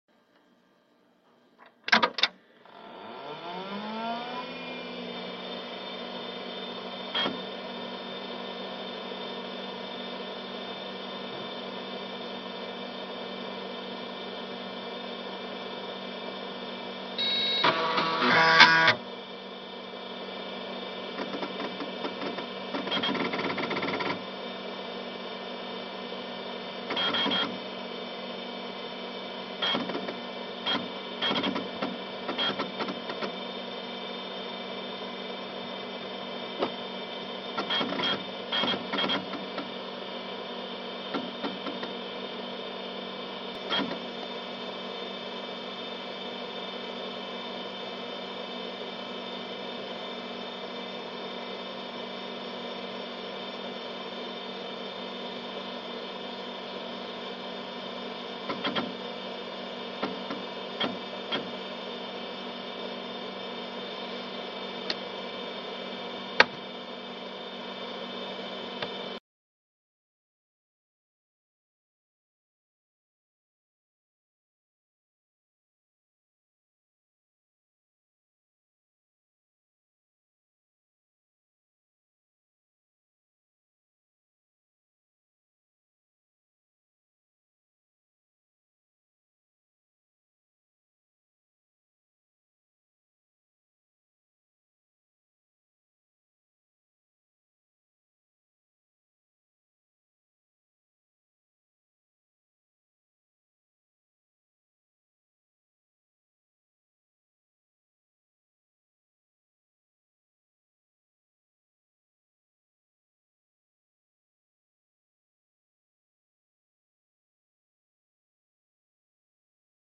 Starting an 1988 Philips NMS9100 sound effects free download
Starting an 1988 Philips NMS9100 and playing the game Commander Keen.